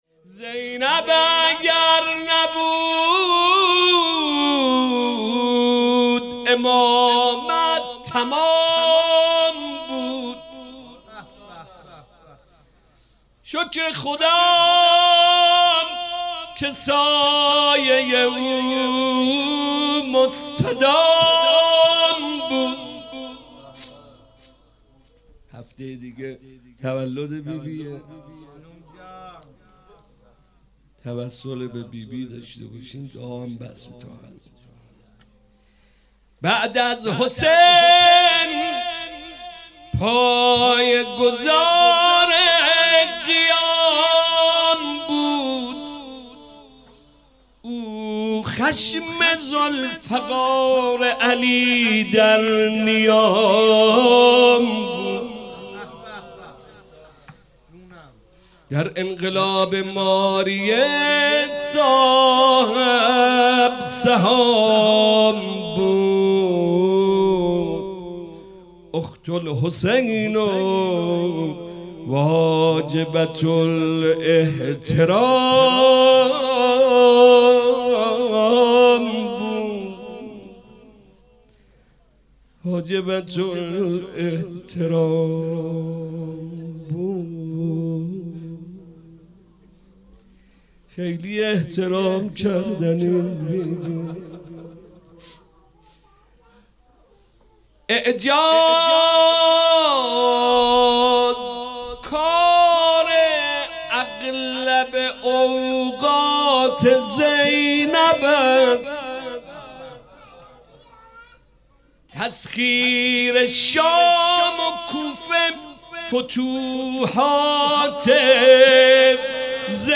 دعای کمیل 13 دی 97 - روضه‌ حضرت زینب سلام الله علیها